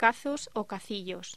Locución: Cazos o cacillos
voz